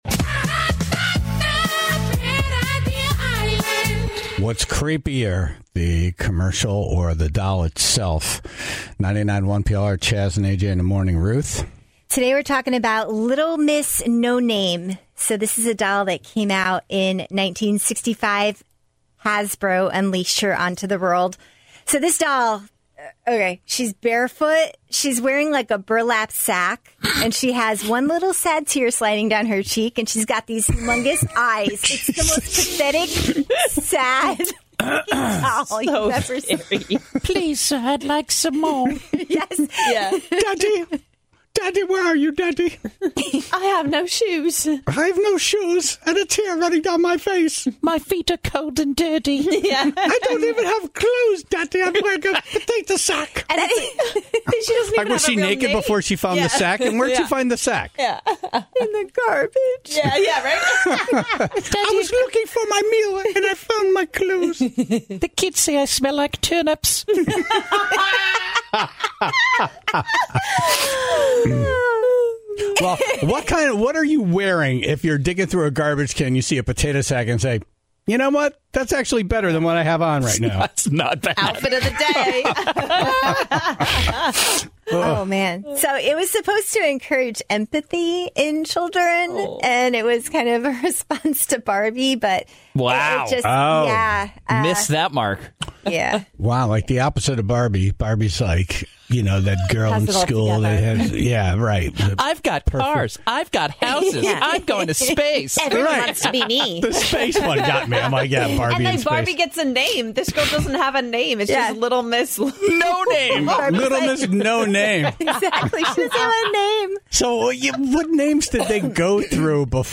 (0:00) In Dumb Ass News, tourists on a safari vacation pissed off the elephants, and wound up getting rammed and dumped into the water. (4:40) The Tribe called in to talk about the dangerous vacations they've been on, including Antarctica and swimming with the whales.